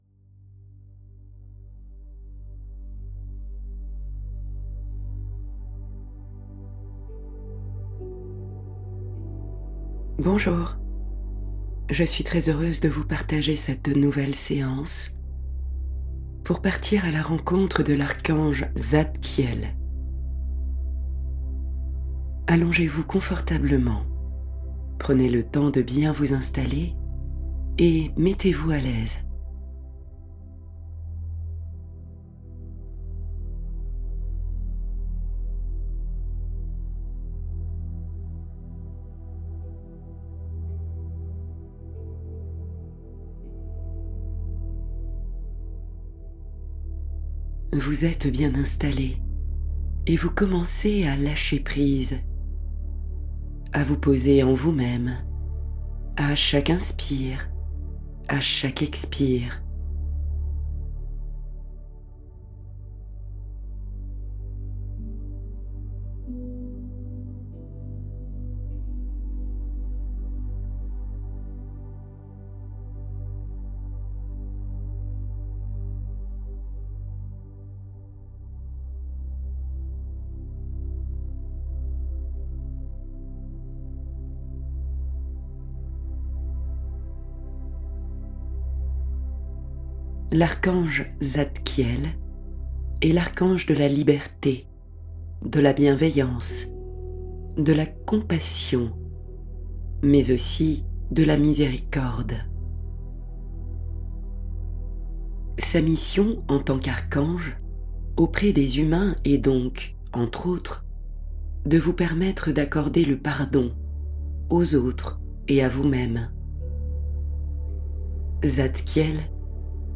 13 méditations guidées pour se relier aux présences lumineuses qui veillent sur vous.
• Coffret voix seule immersion totale offert – 13 versions sans musique pour une pratique différente